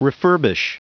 Prononciation du mot refurbish en anglais (fichier audio)
Prononciation du mot : refurbish